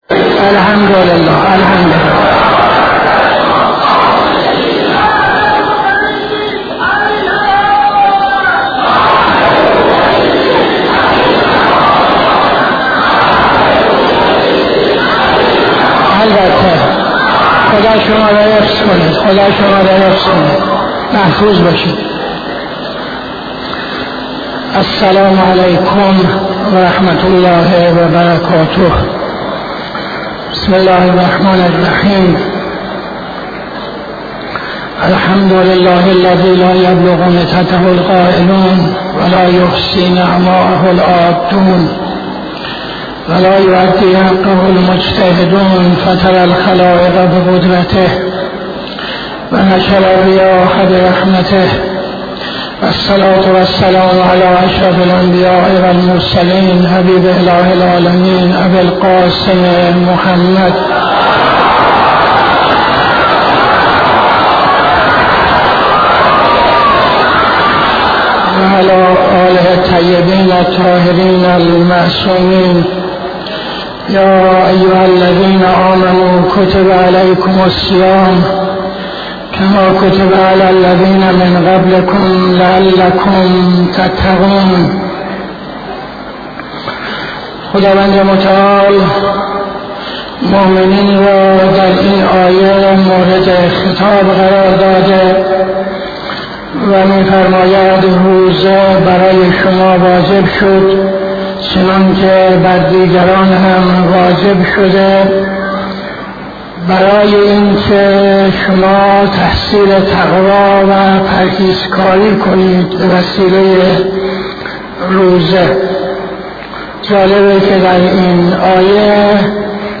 خطبه اول نماز جمعه 19-09-78